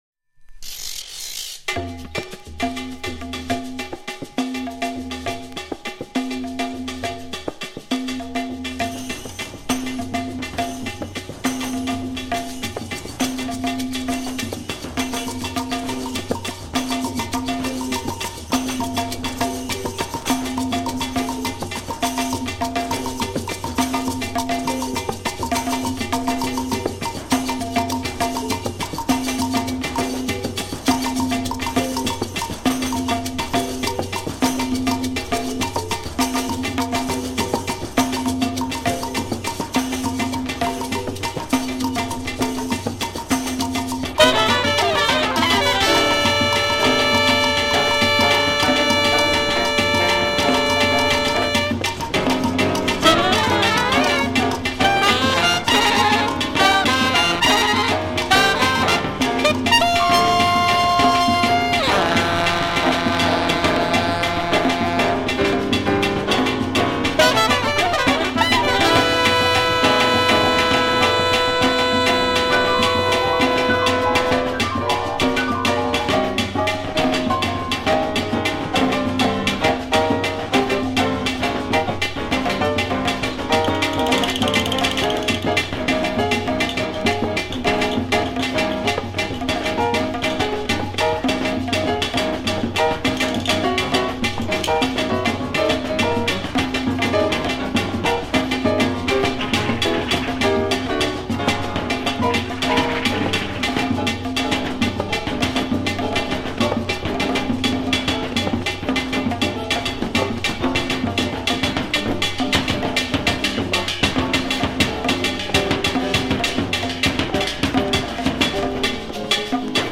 エキゾチックで炸裂感のある